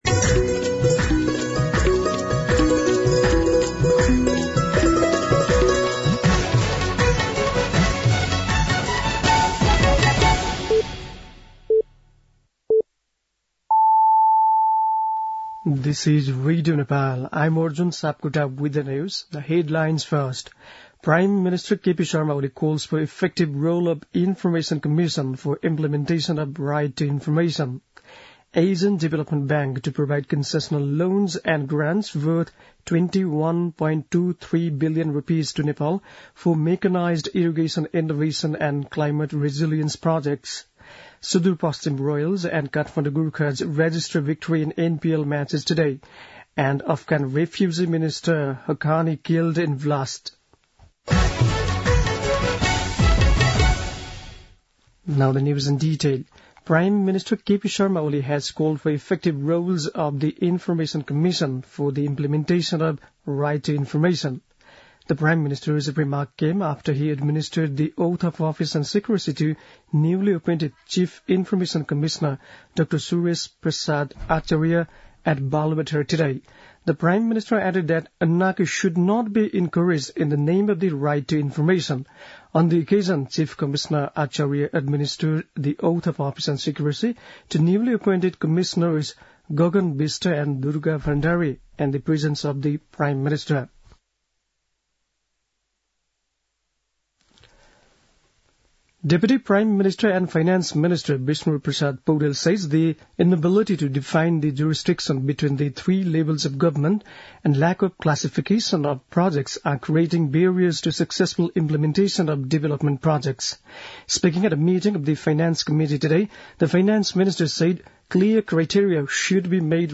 बेलुकी ८ बजेको अङ्ग्रेजी समाचार : २७ मंसिर , २०८१